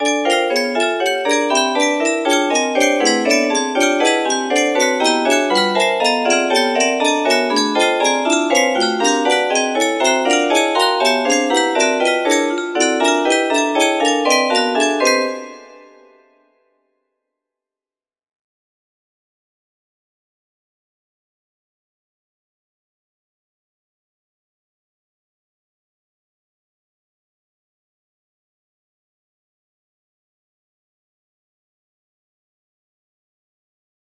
P20 music box melody